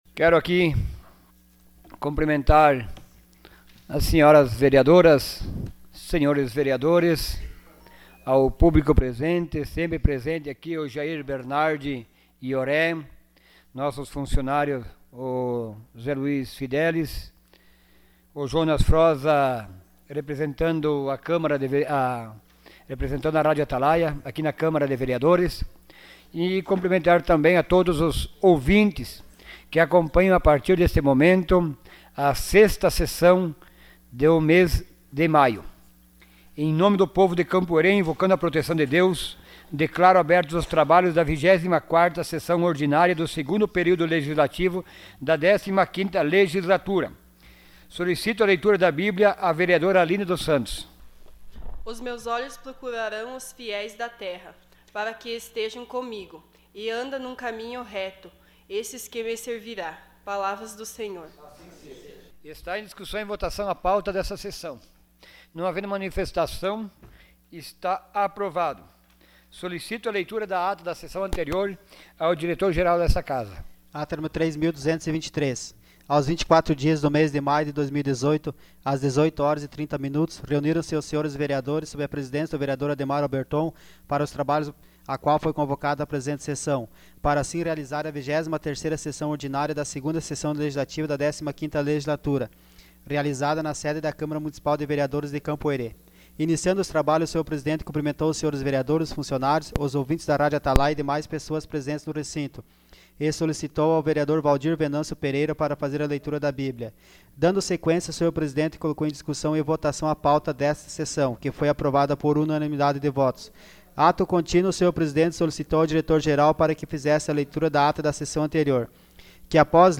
Sessão Ordinária dia 30 de maio de 2018.